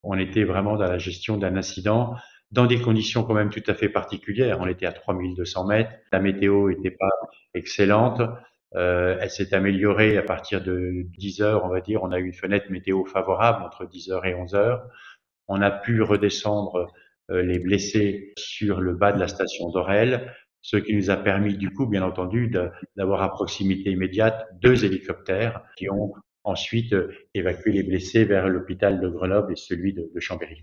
Le Préfet de la Savoie, François Ravier, revient lui sur les opérations de secours mardi matin :